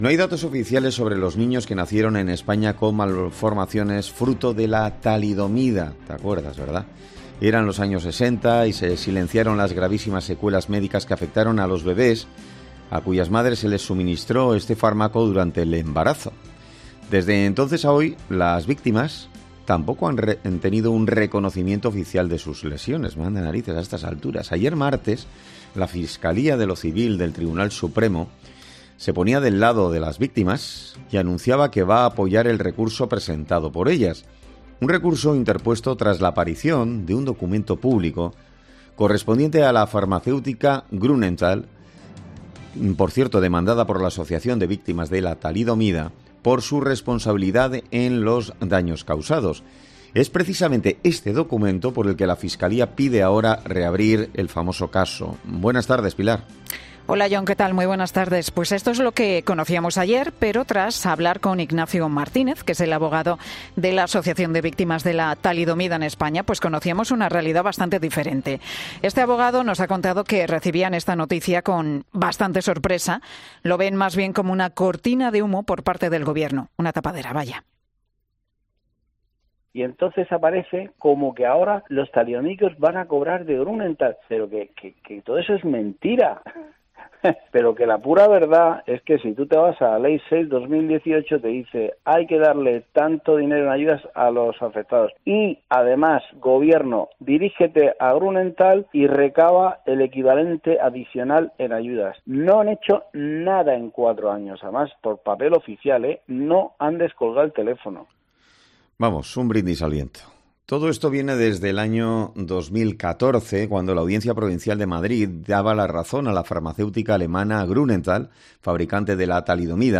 En ‘Herrera en COPE’ hablamos con